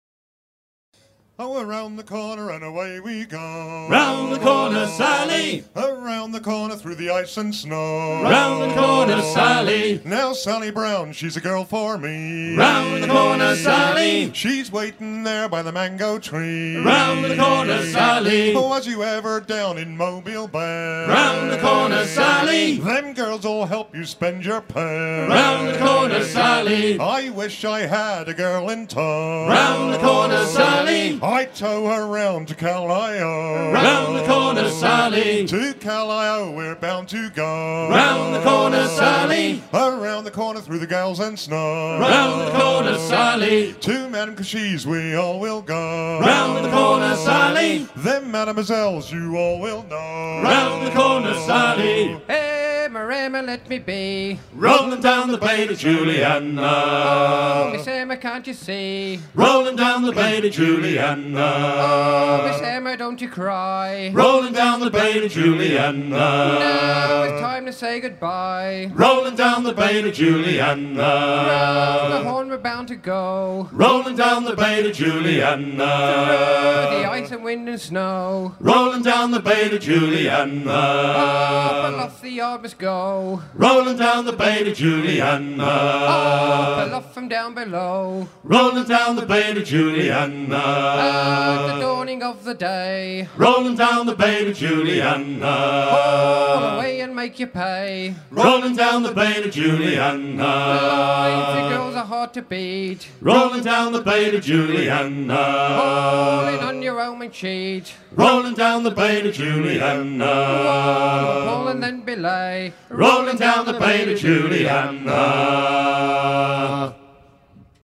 chanteys
à hisser main sur main
Douarnenez port de fête, Vol. 17